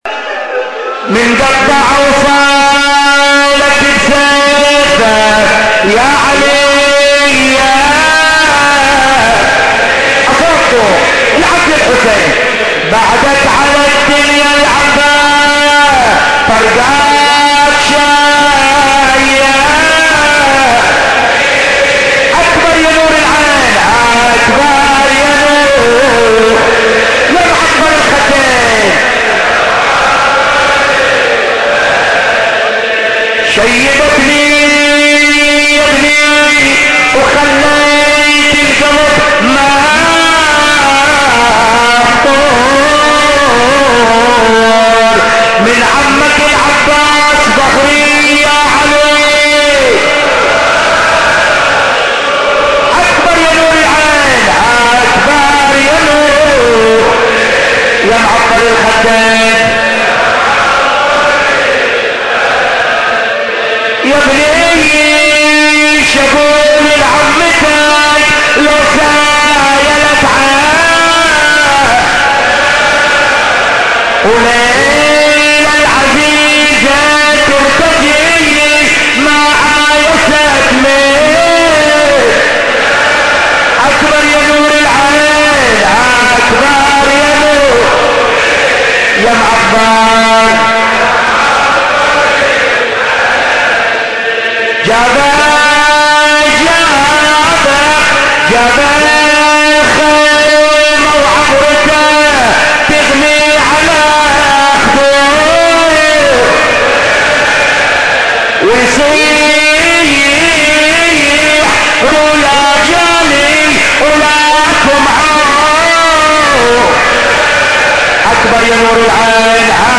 أبيات حسينية – الليلة التاسعة من محرم – الجزء الثاني